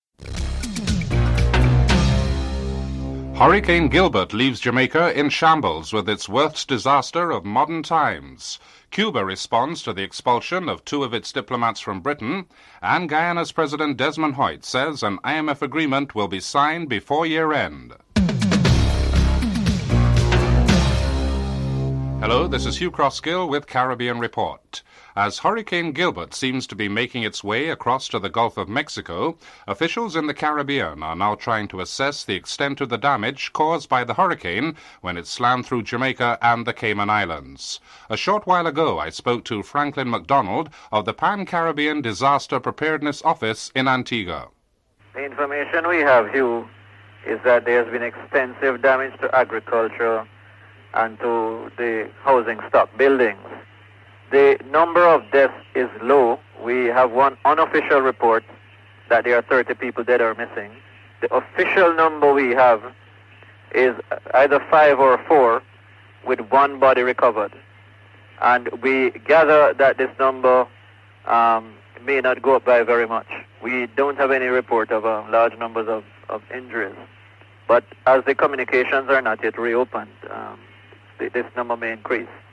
1. Headlines(00:00-00:24)